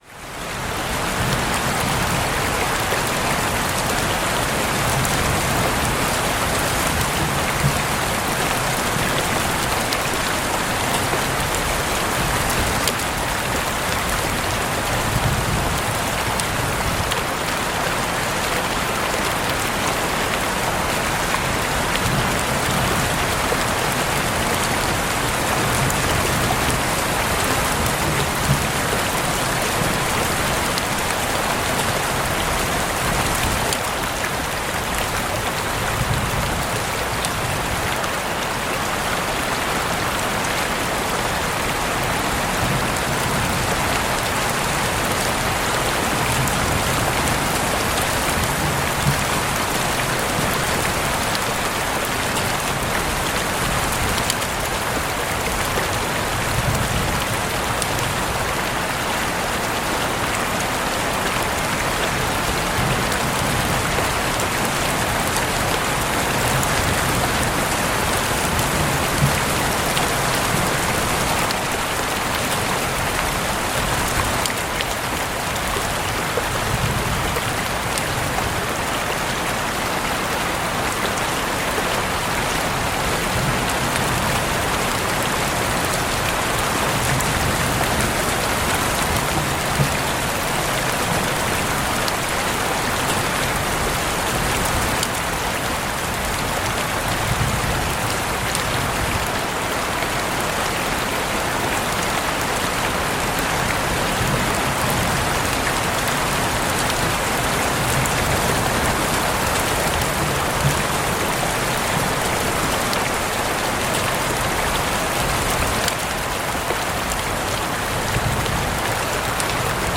Camping Rain & Green Noise for Peaceful Sleep in Nature